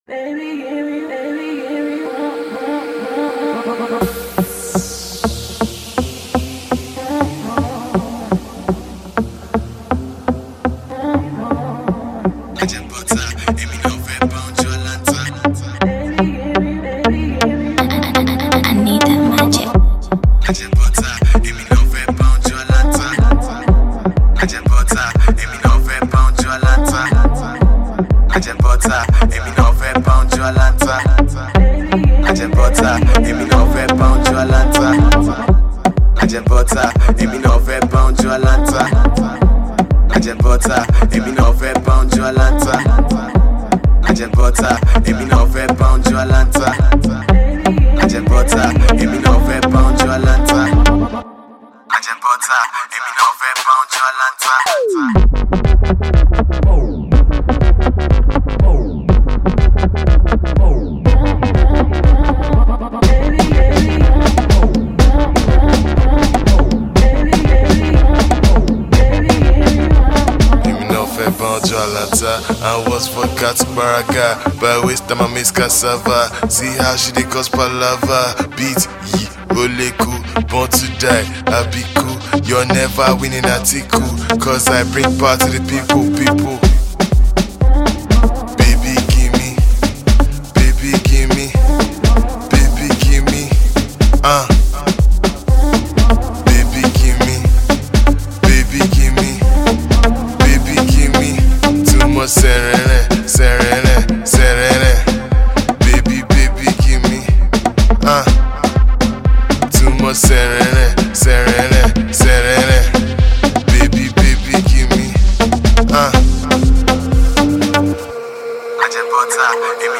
is a very fun and playful track